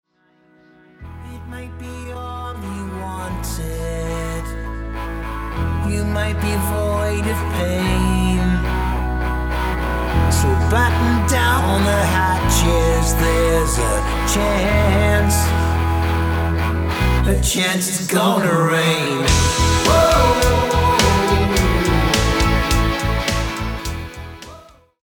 Very Dark